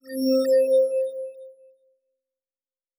Teleport 10_3.wav